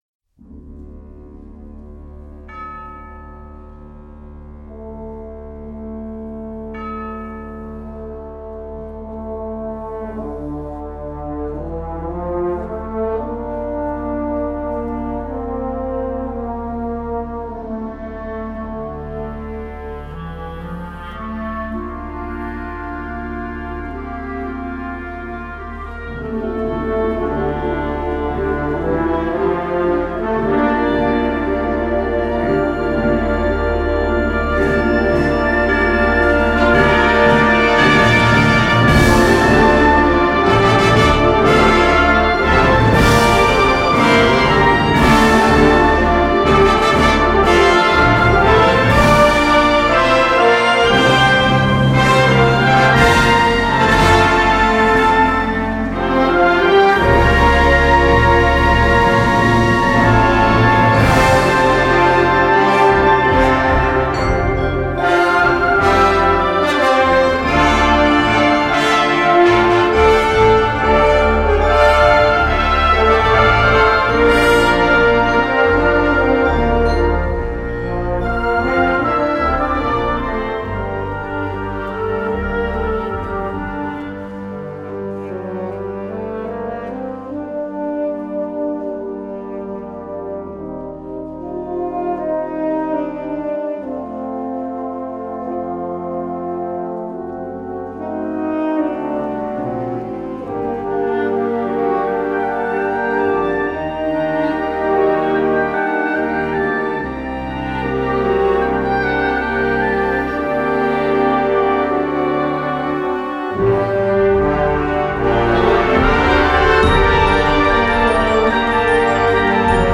Partitions pour orchestre d'harmonie.